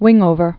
(wĭngōvər)